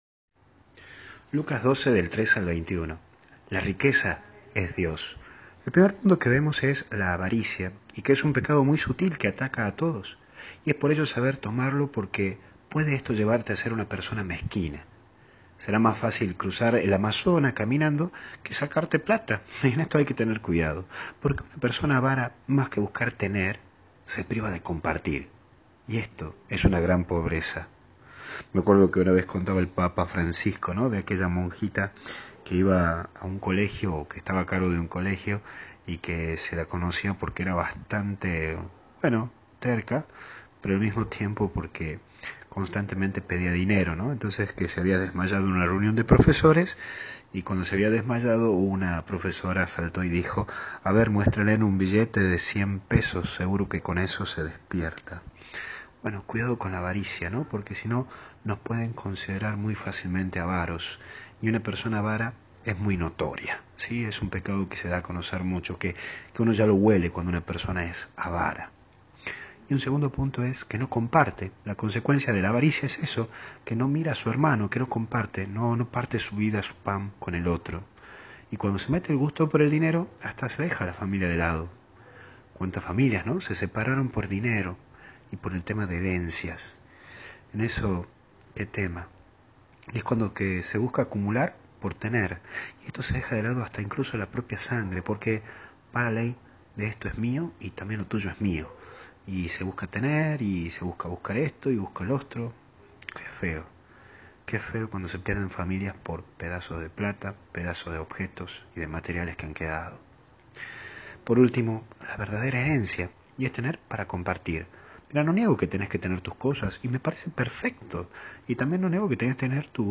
Meditación Diaria